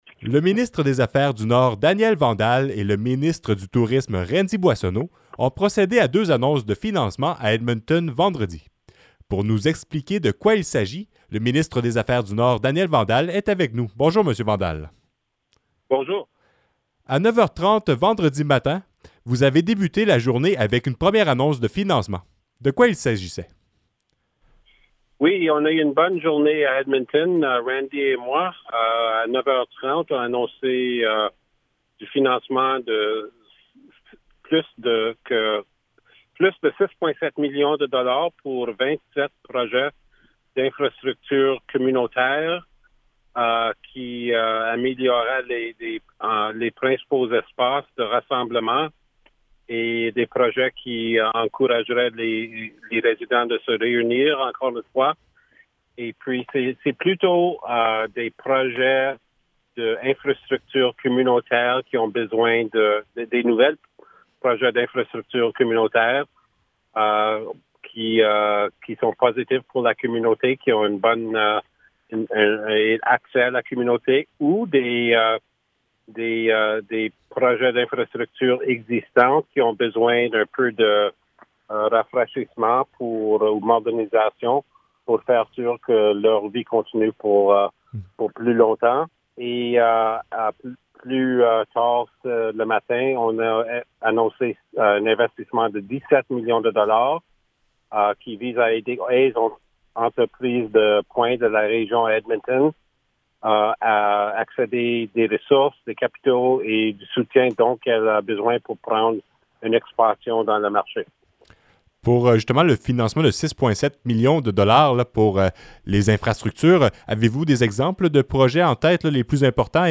Entrevue-Daniel-Vandal-Integrale.mp3